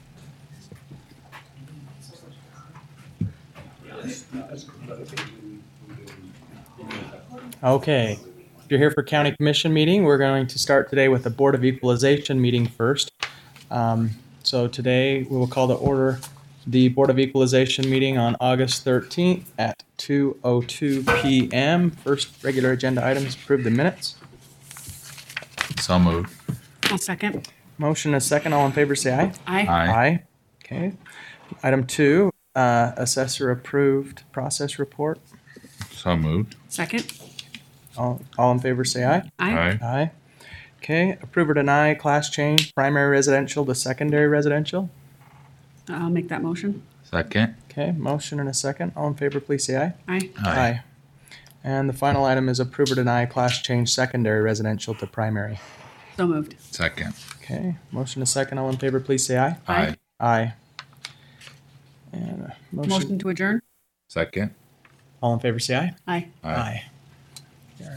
Notice, Meeting
100 E Center Street, Room 1400